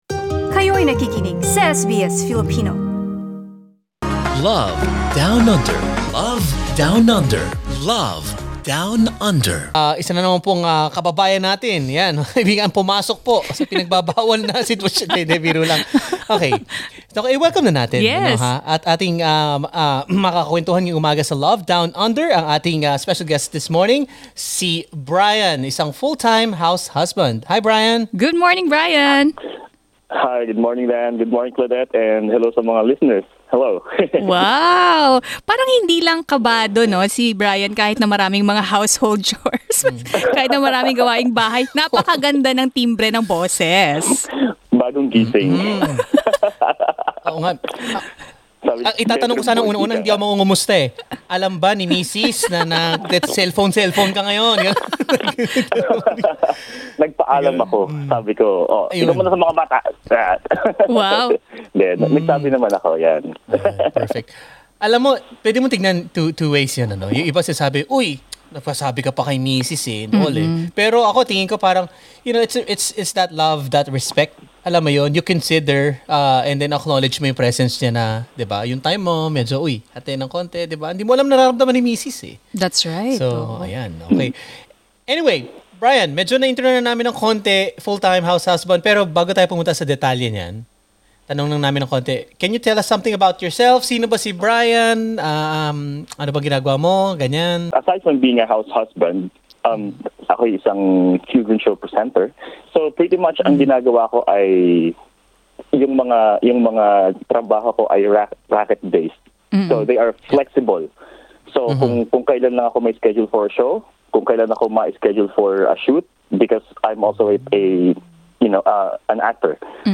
In this love down under episode, we speak to a stay-at-home dad on why he chose to switch roles with his wife.